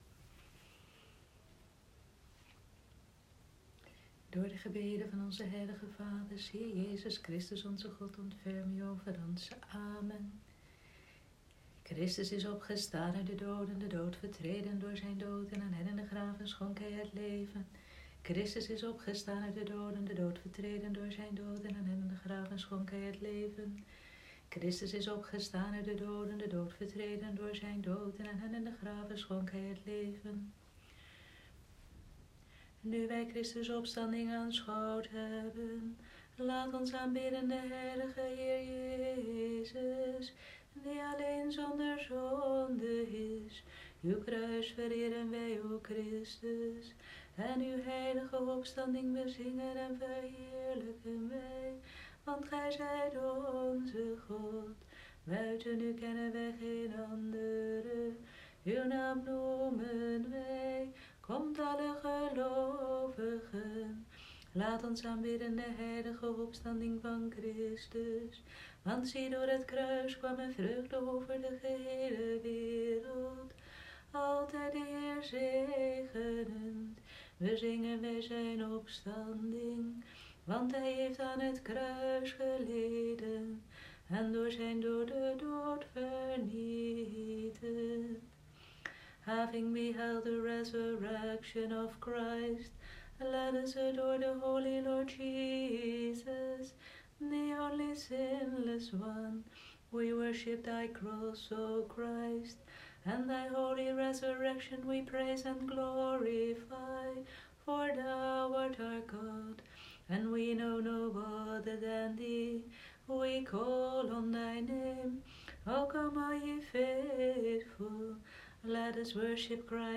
Paasuur en Vespers van Stralende Vrijdagavond, 24 april 2020